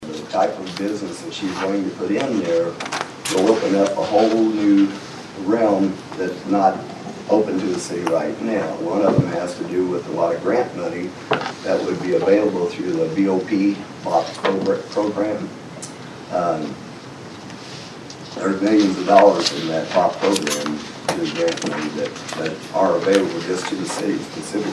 There was a long discussion at Monday’s Vandalia City Council meeting on the possible sale of the former First Baptist Church building in downtown Vandalia.